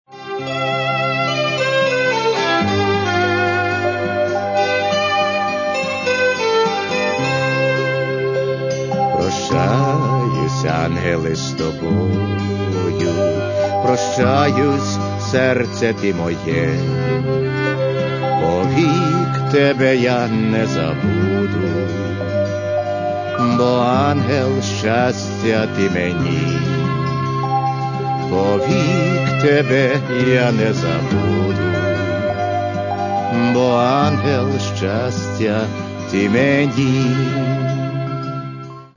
Каталог -> Эстрада -> Певцы